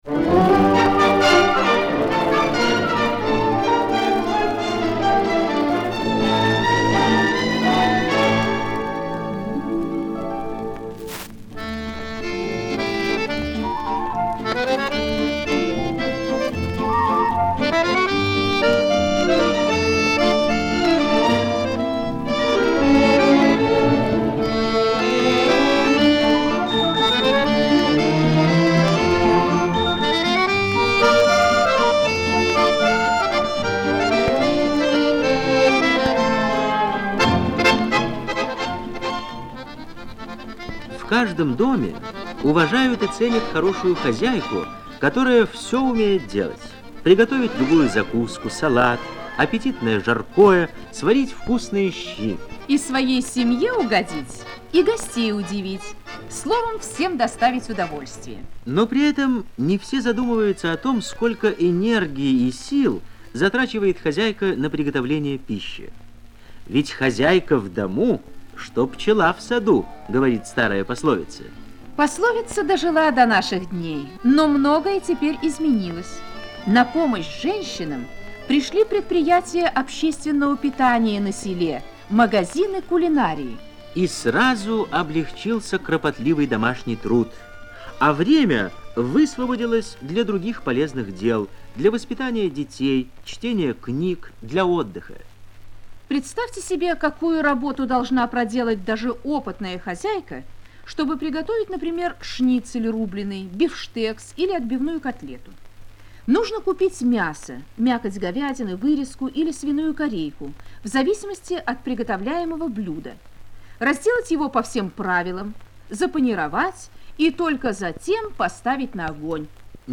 Социальная реклама в СССР